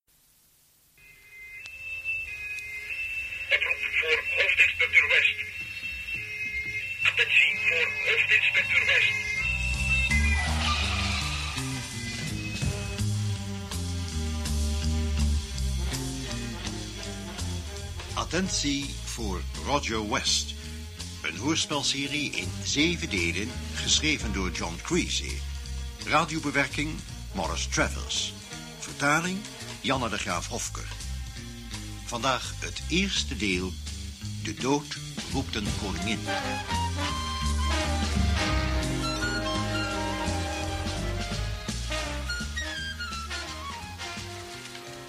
Intro-muziek: